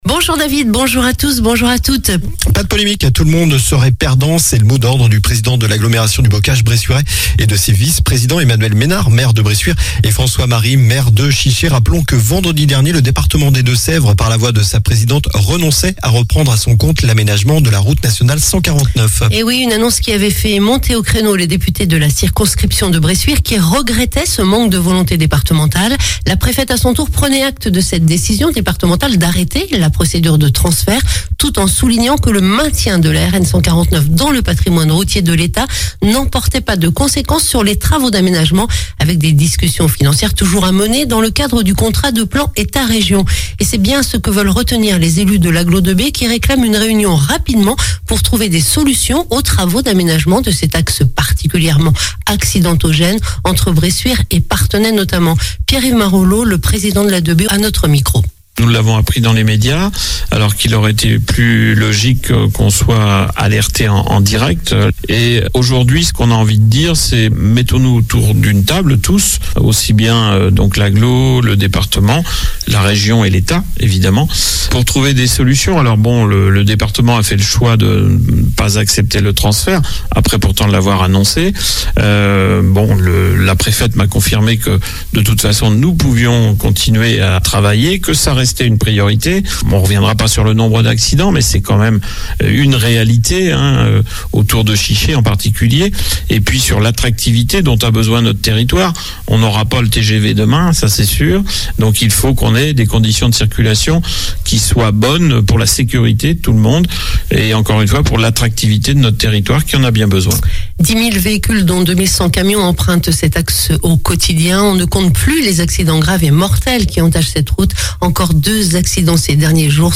Journal du jeudi 22 décembre